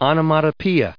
When you pronounce the word splash , it sounds like it’s meaning – such as the sound when someone jumps into water. There are many other words like this in English that sound like their meaning (squeak, buzz, crack, poof…).